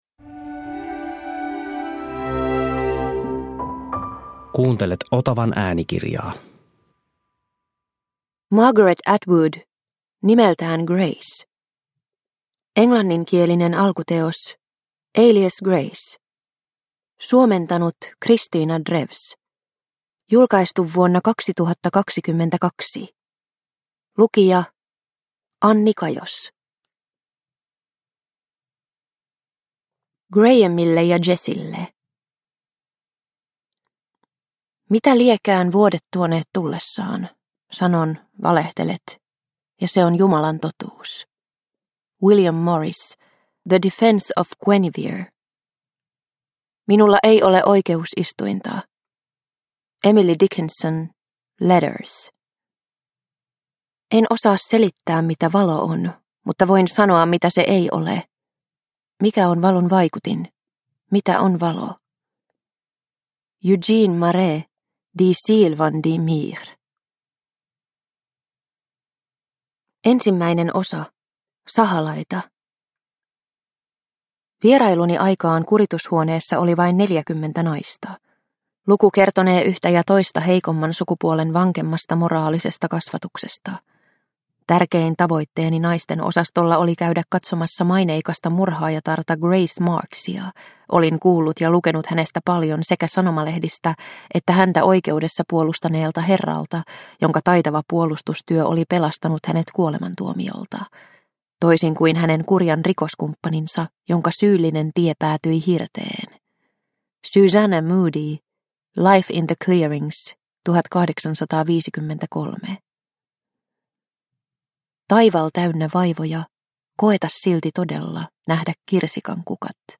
Nimeltään Grace – Ljudbok – Laddas ner